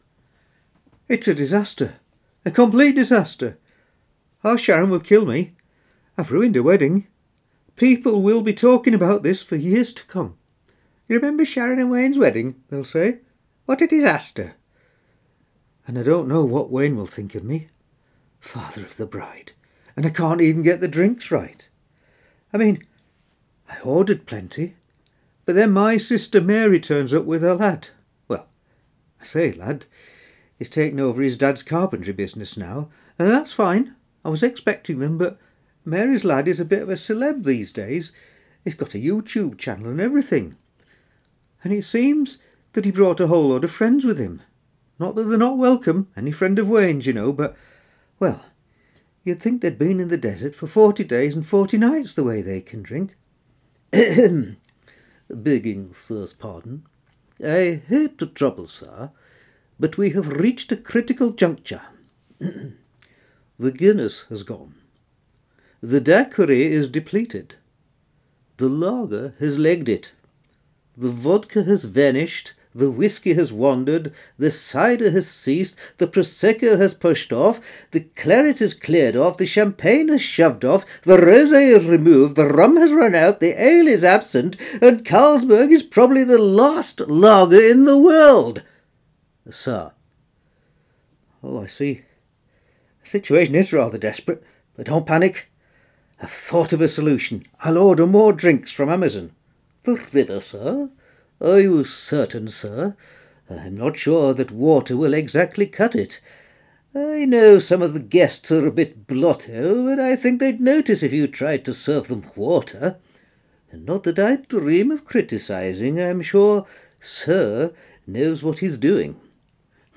• Father of the Bride – main character, flustered
• Head Waiter – rather pompous
• Bartender – practical